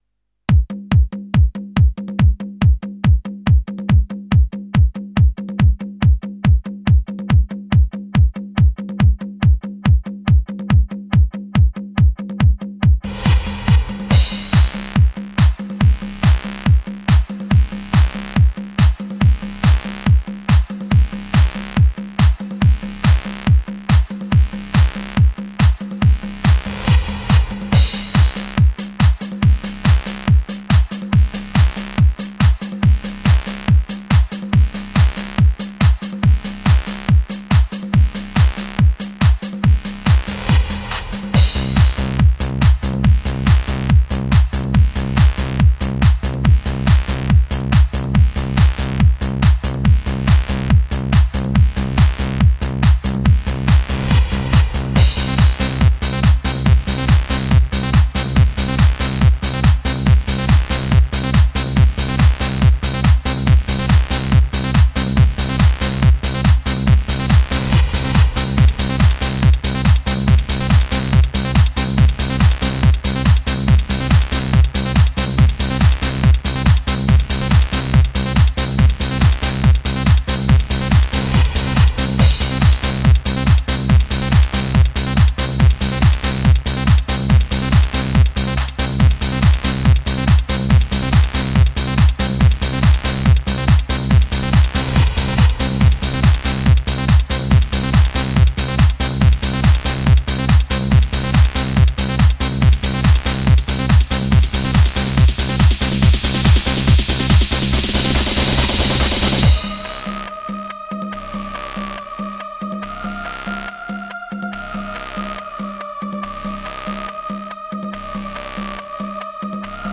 Жанр: HardTrance